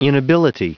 Prononciation du mot inability en anglais (fichier audio)
Prononciation du mot : inability